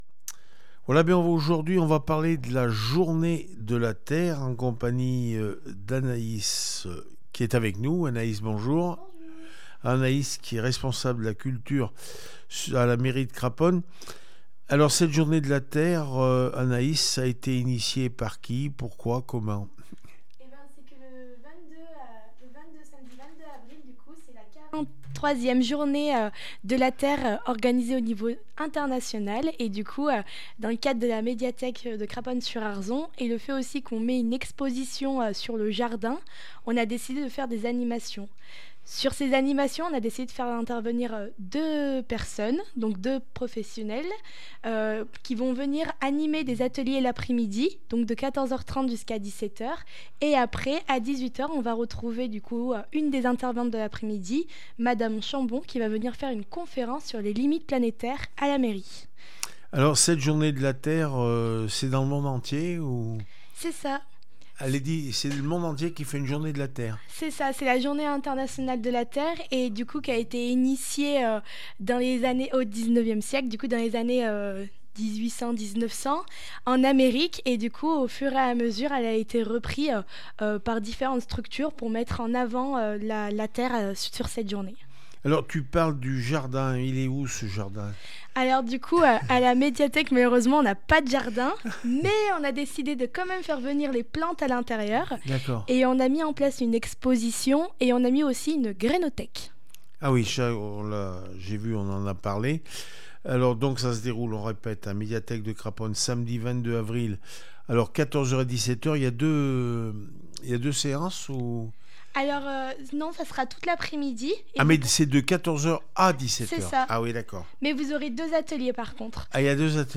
20 avril 2023   1 - Vos interviews, 2 - Culture